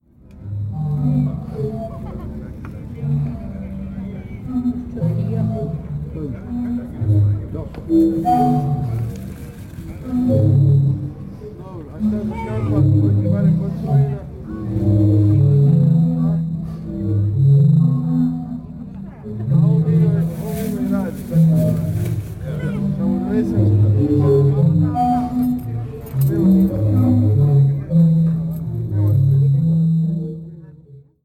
… und die Meeresorgel, …
… deren Pfeifen, durch die Strömung angetrieben, ein wenig wie große Wahle unter Wasser klingen:
zadar-meeresorgel.mp3